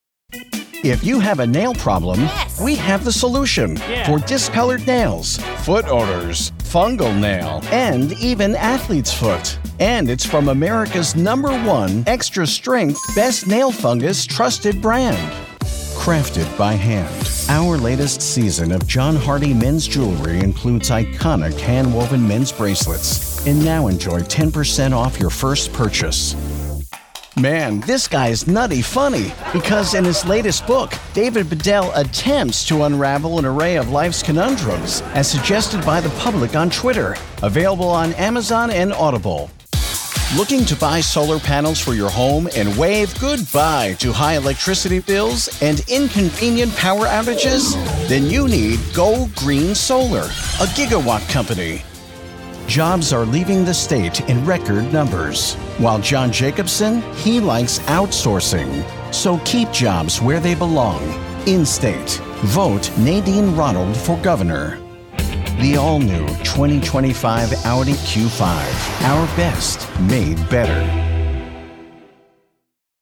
English (American)
Believable
Sincere
Energetic